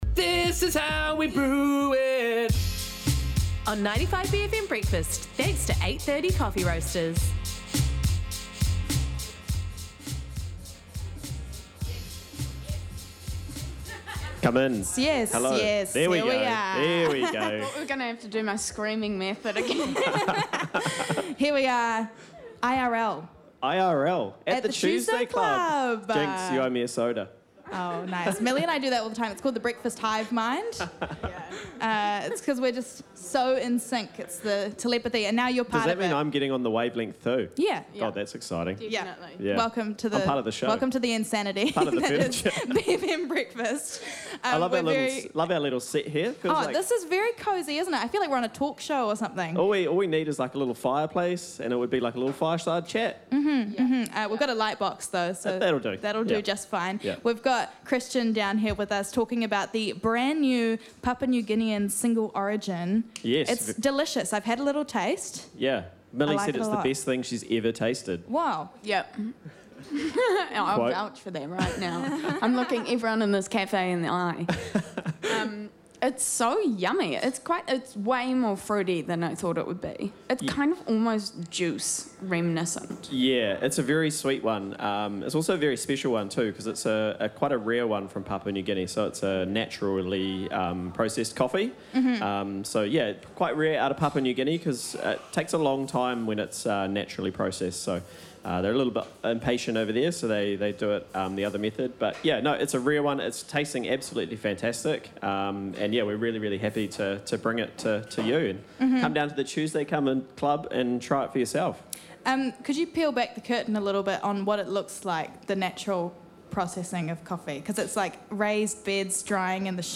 live at the Tuesday Club for 95bFM Breakfast Club! They chat about the latest Papa New Guinea Kindeng single origin and all the excitement happening at 42 Airedale Street this morning.